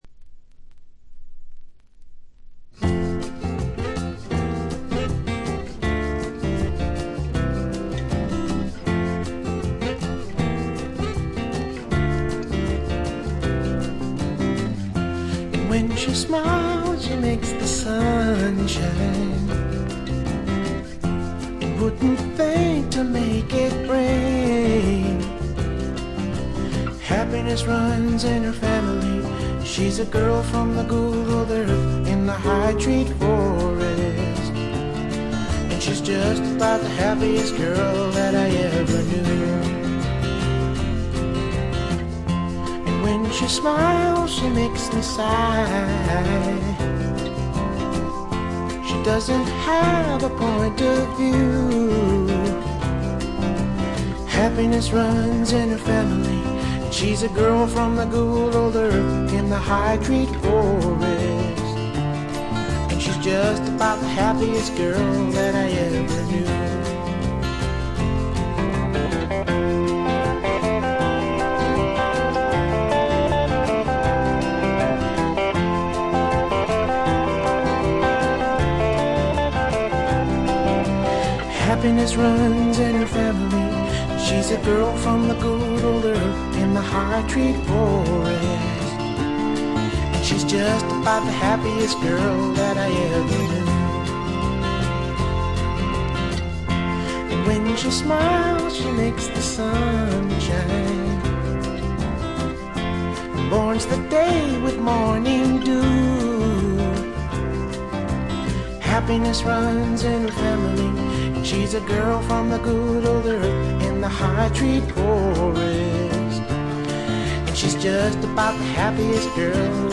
カントリー風味を加えています。カントリーロック的な曲とフォークロック的な曲の組み合わせ加減もとてもよいです。
Guitar, Guitar (Rhythm), Vocals